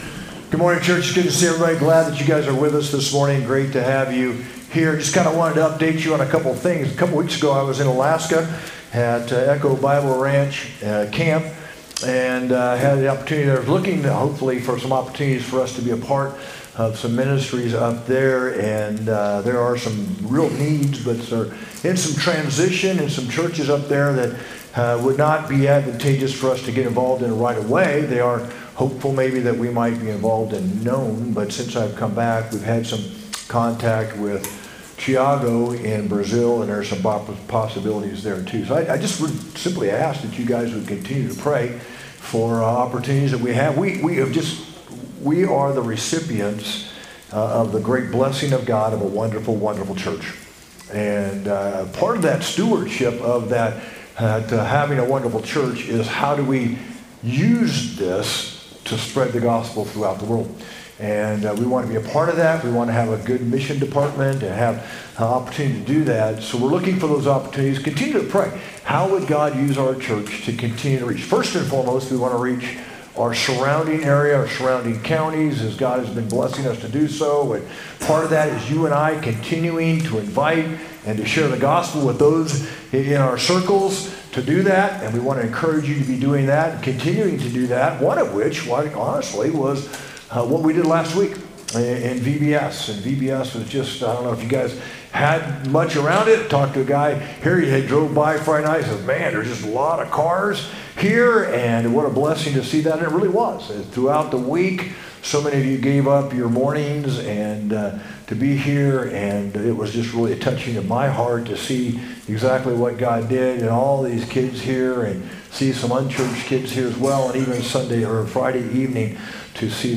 sermon-6-8-25.mp3